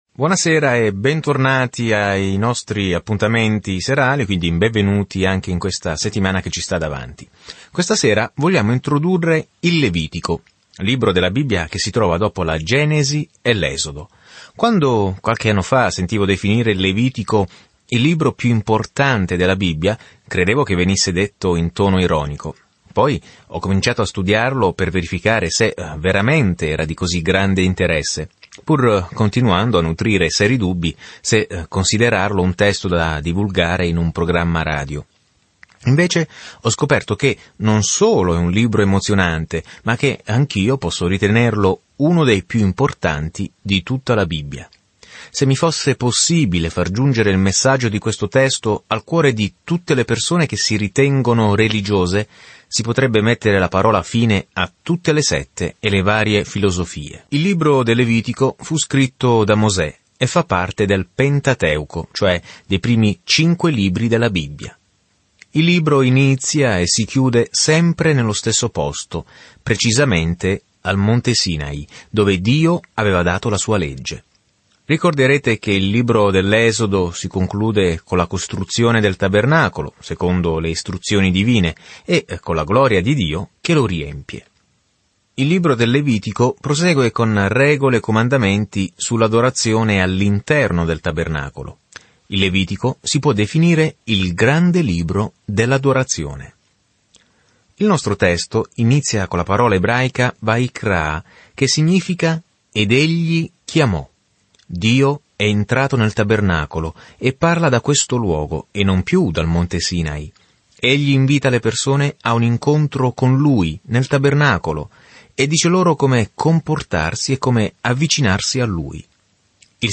Nell'adorazione, nel sacrificio e nella riverenza, il Levitico risponde a questa domanda per l'antico Israele. Viaggia ogni giorno attraverso il Levitico mentre ascolti lo studio audio e leggi versetti selezionati della parola di Dio.